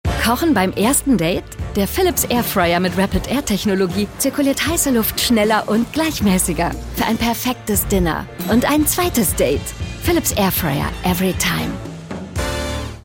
sehr variabel
Mittel plus (35-65)
Norddeutsch
Audio Drama (Hörspiel)